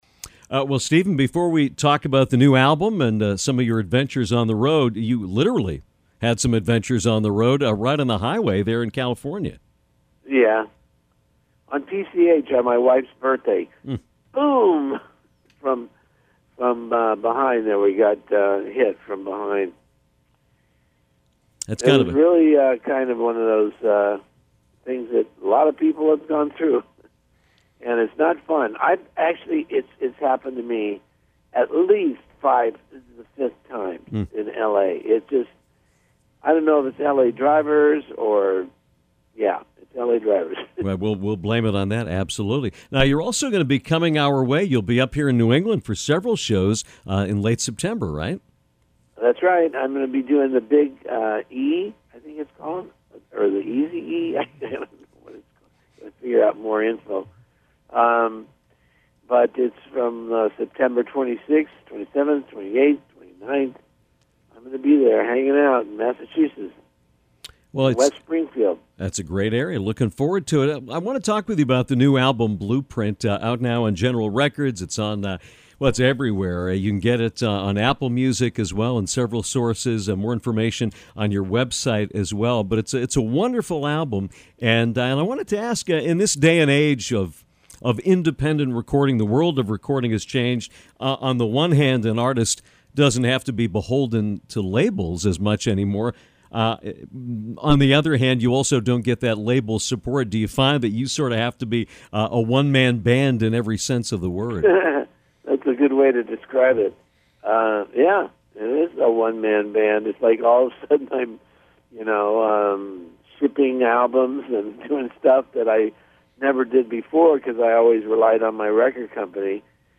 This is an edit of our fascinating interview with singer and songwriter Stephen Bishop from last week.